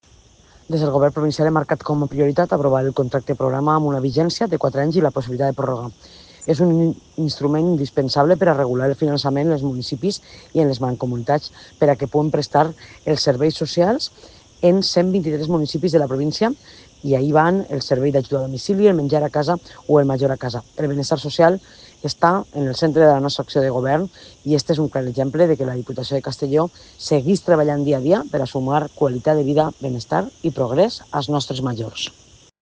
Presidenta-Marta-Barrachina-Contrato-Programa.mp3